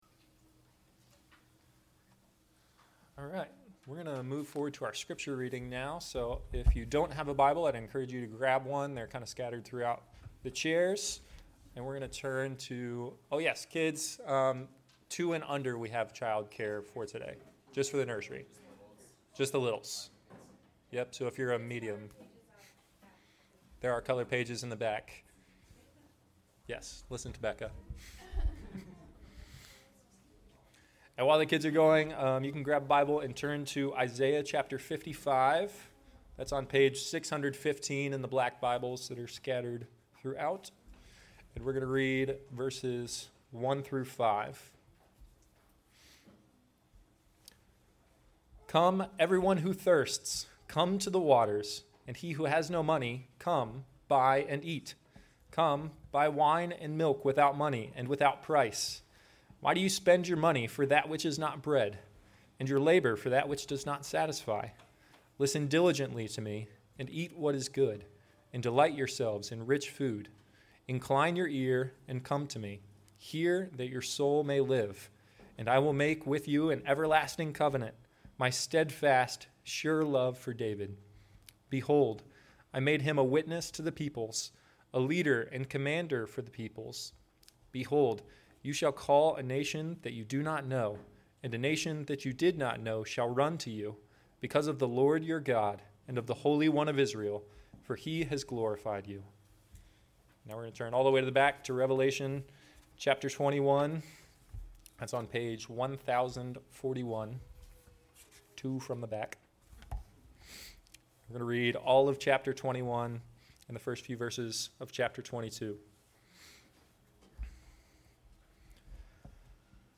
Sermons | Christ Presbyterian Church